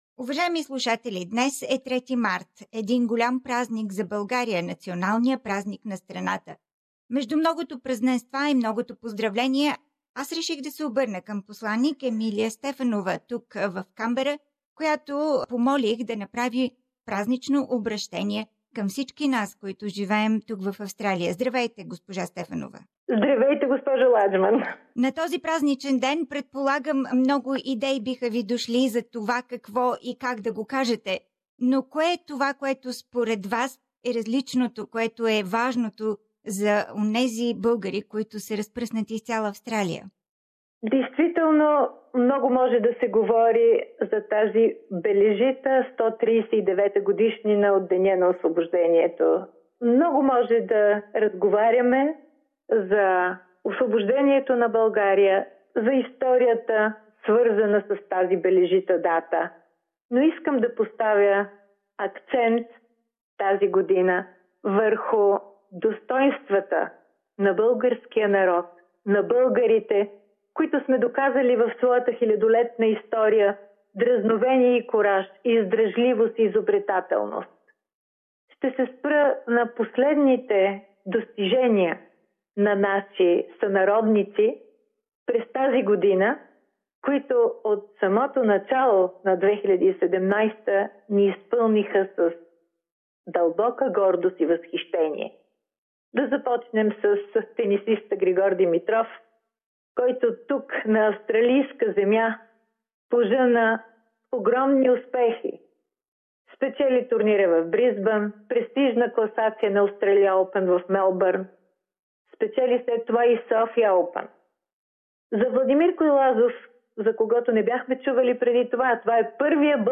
Formal address by Bulgarian Ambassador in Australia - Emilia Stefanova-Veleva Ambassador Extraordinary and Plenipotentiary.